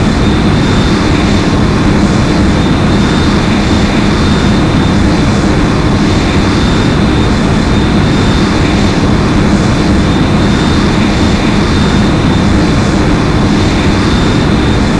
nf_dropship_loop.wav